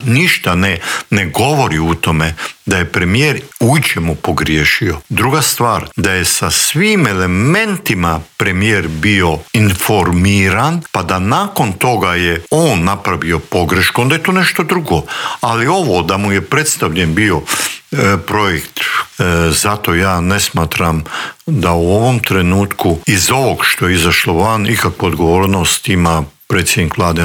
ZAGREB - Povodom drugog izdanja knjige ‘Diplomatska oluja - sjećanja najdugovječnijeg Tuđmanovog ministra‘, u Intervjuu tjedna Media servisa gostovao je bivši ministar vanjskih poslova i posebni savjetnik premijera Mate Granić.